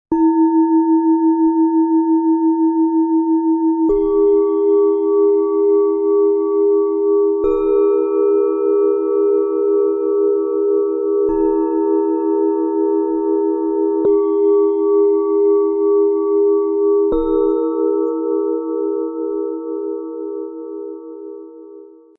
Leichtigkeit des Seins: fröhlich, erfrischend, befreiend - Klangmeditation - Set aus 3 Klangschalen, Ø 13 - 14,5 cm, 1,69 kg
Die tiefste Schale bietet eine kraftvolle Basis und schenkt ein Gefühl von innerer Stabilität und sanfter Erdung.
Mit ihrem hellen Klang bringt sie pure Lebensfreude und erinnert daran, das Schöne und Leichte im Leben zu umarmen.
Im Sound-Player - Jetzt reinhören können Sie den Original-Ton genau dieser Klangschalen des Sets Leichtigkeit des Seins anhören. Erleben Sie die besondere Mischung aus Tiefe, Fröhlichkeit und himmlischer Leichtigkeit.
Bengalen Schale, Schwarz-Gold, 14,5 cm Durchmesser, 7,1 cm Höhe
MaterialBronze